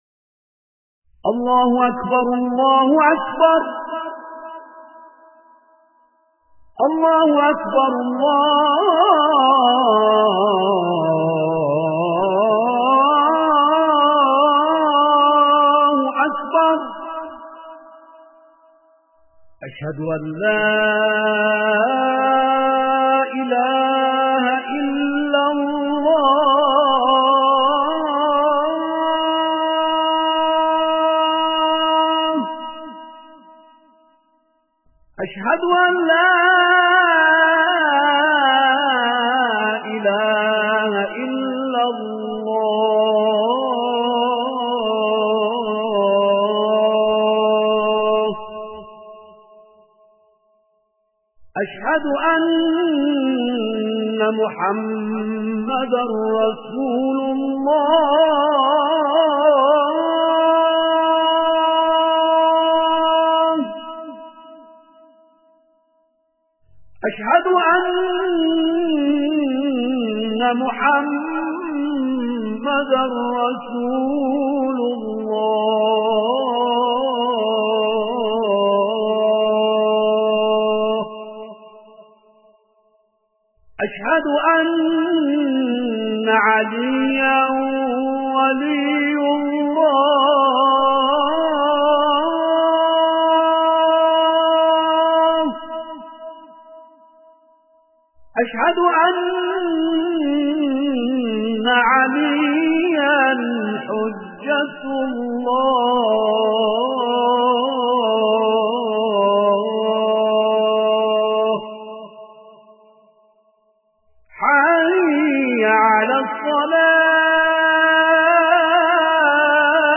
اذان